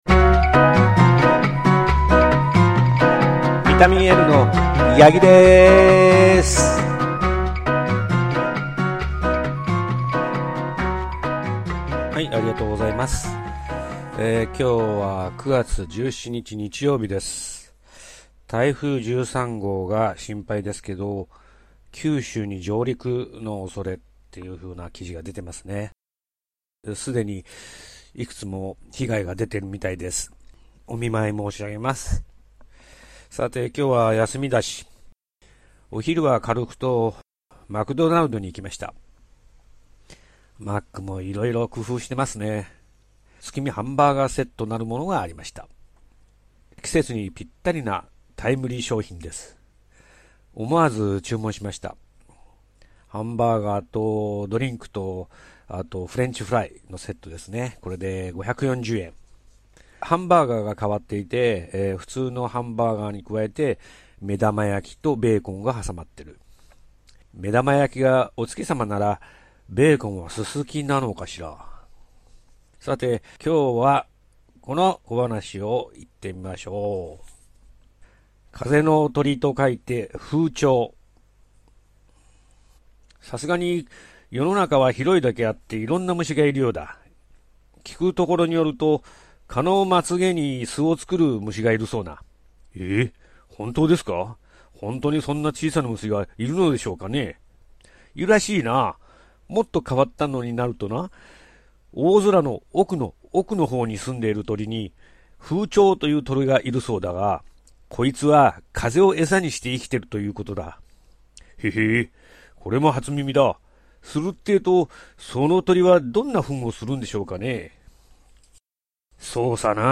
2006年9 月17日 (日) 風鳥 今日は「風鳥（ふうちょう）」と言うお題の江戸小話です。